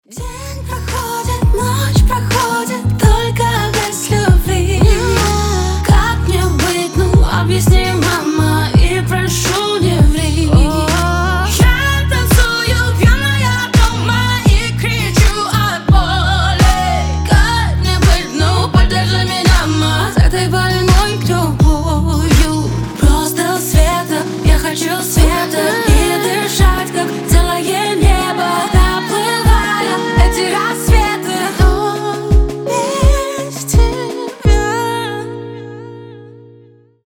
Грустные RnB